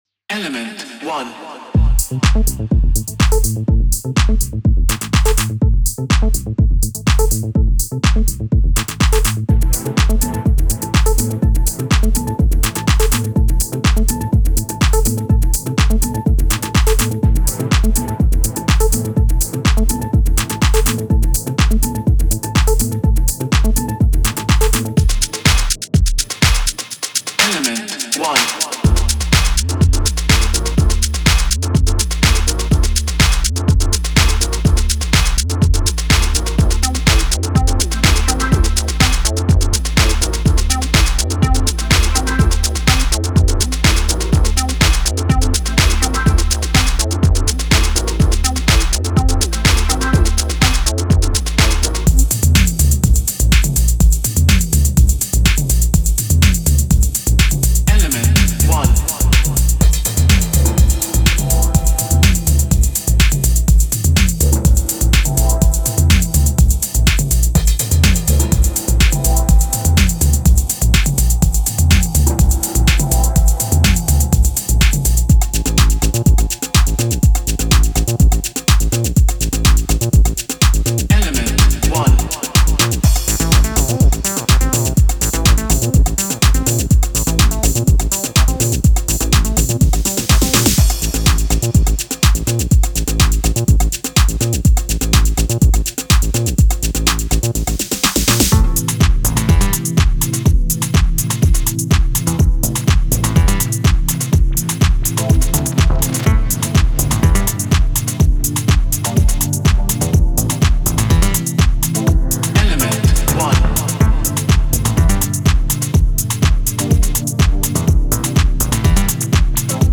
Genre:Tech House
クリスプなミニマルドラム、ウォームなサブベース、アトモスフェリックなテクスチャーが完璧に調和した空間。